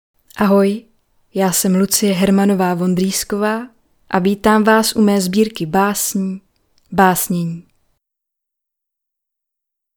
báSNĚNÍ audiokniha
Ukázka z knihy